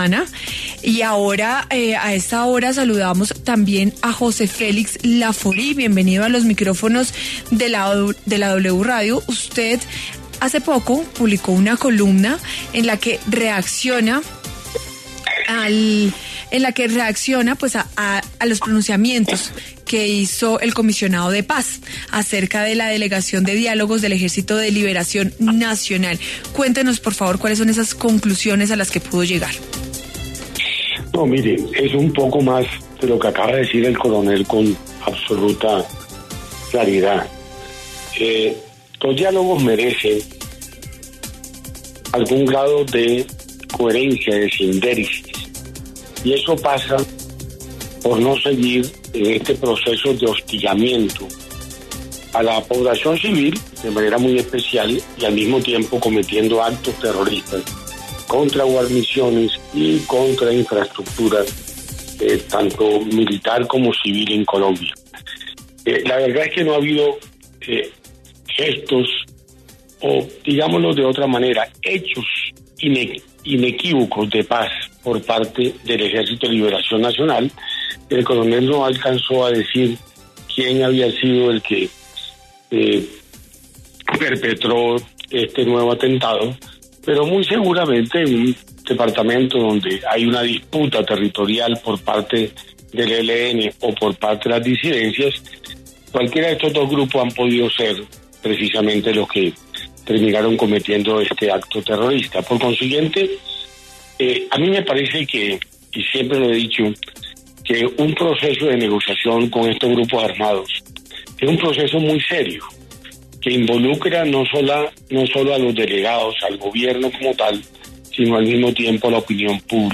Ante esto, Lafaurie pasó por los micrófonos de W Fin de Semana asegurando que “los diálogos merecen algún grado de coherencia y eso pasa por no seguir este proceso de hostigamiento a la población civil, de manera muy especial, y al mismo tiempo cometiendo actos terroristas contra guarniciones y contra infraestructuras”.